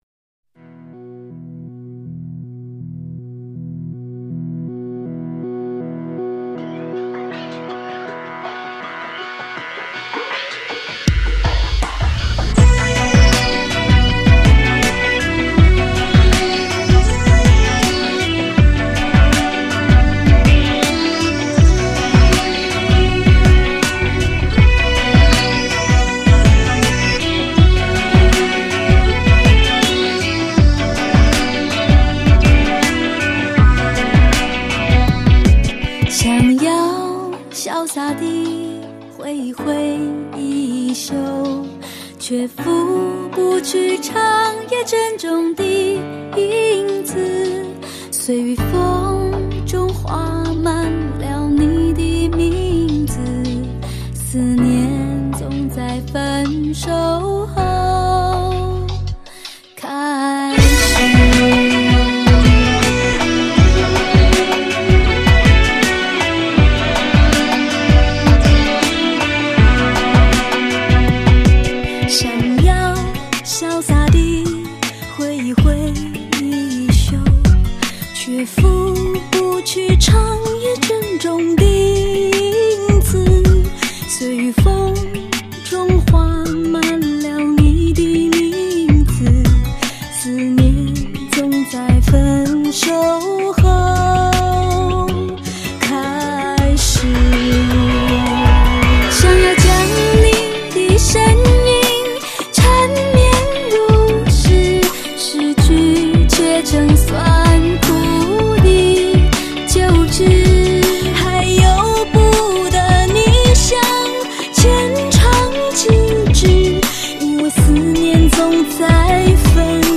风格： China-Pop